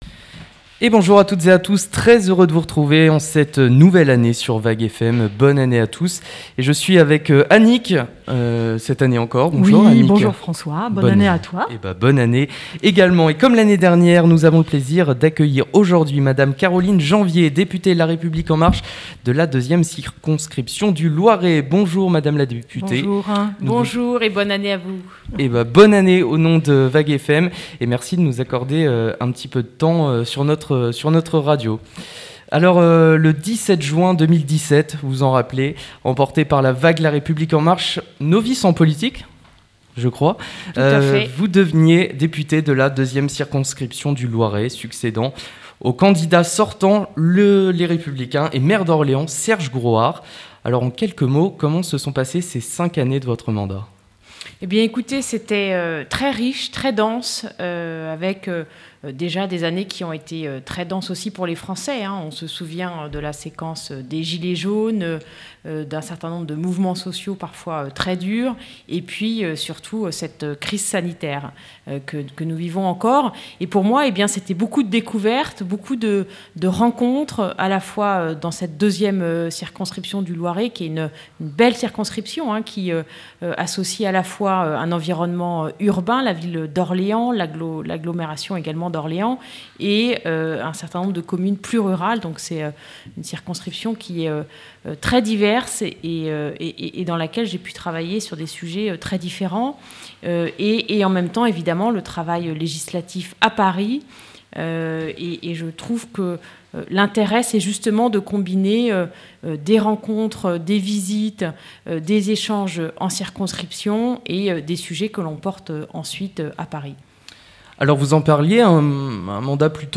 Interview Caroline Janvier
Interview de Caroline JANVIER députée de la 2ème circonscription du Loiret